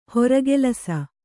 ♪ horagelasa